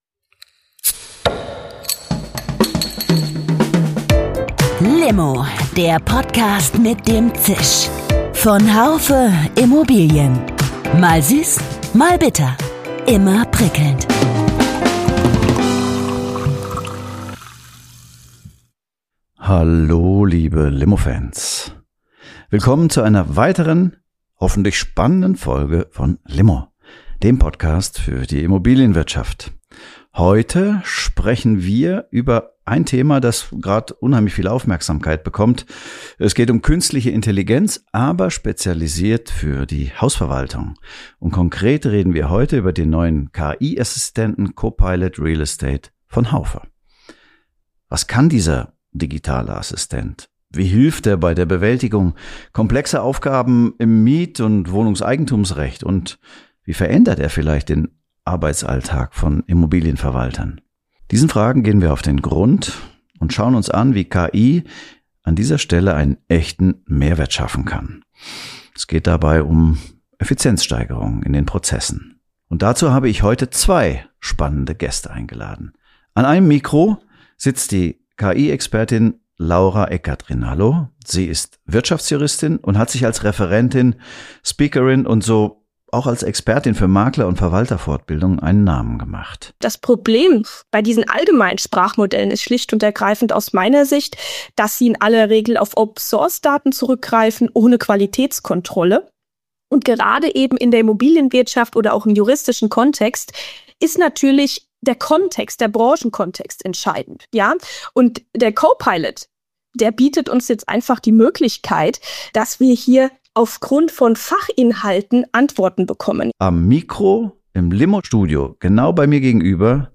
Gemeinsam bringen sie sowohl technische als auch praxisorientierte Perspektiven mit ans Mikrofon.